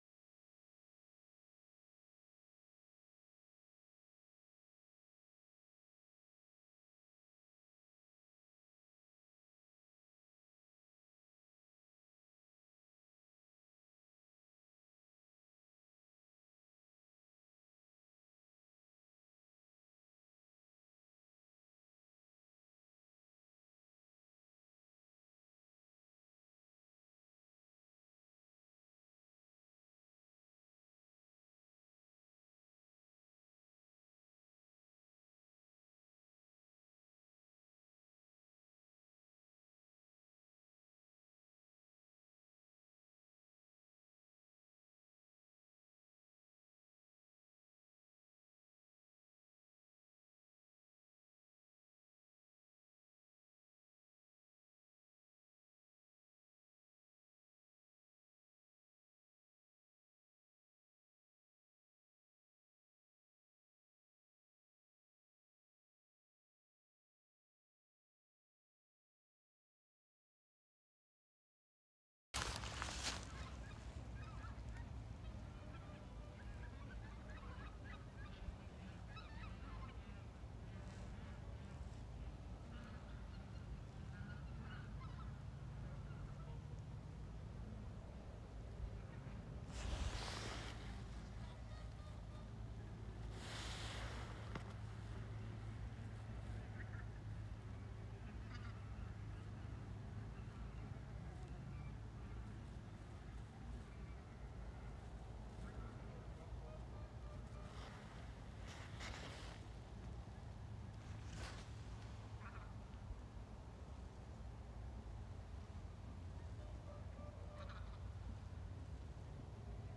Birds -> Swans ->
Whooper Swan, Cygnus cygnus
Ir kopā ar zosīm - meža, baltpieres, sējas.